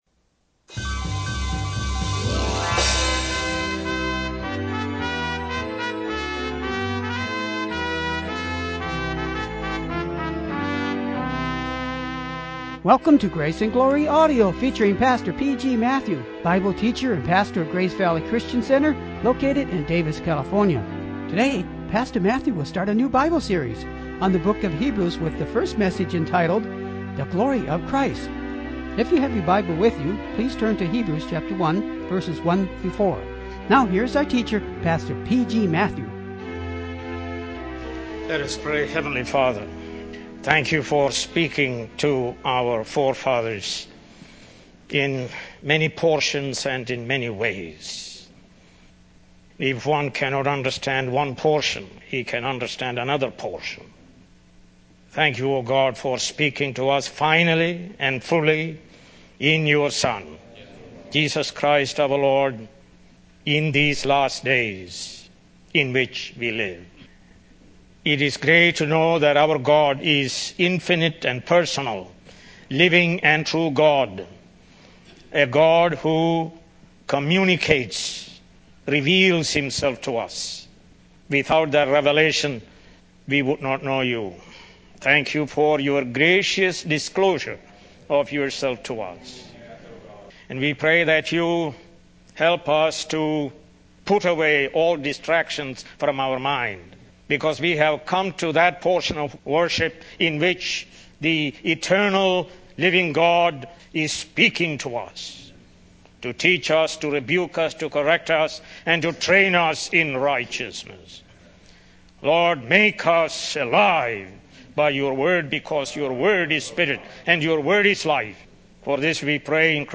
More Sermons From the book of Hebrews